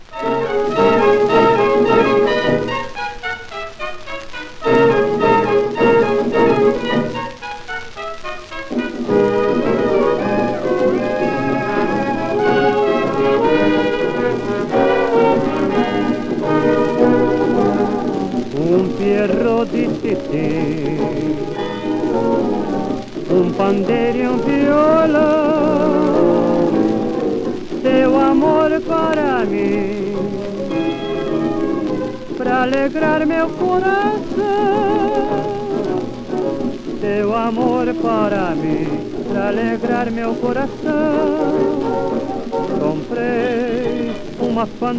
ジャケスレ汚れシール貼付有　盤良好　元音源に起因するノイズ有